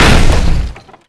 Crash2.wav